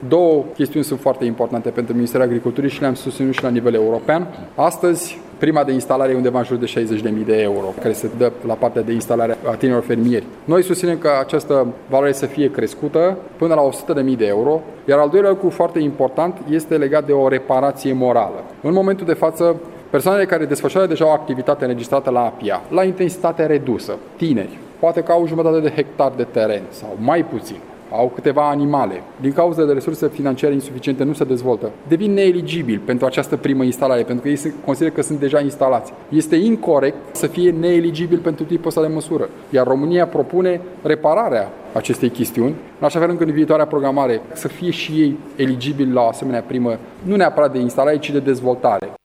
Declaraţiile au fost făcute în cadrul conferinţei regionale dedicate Programului Naţional de Dezvoltare Rurală 2014 – 2020, care a avut la Brăila.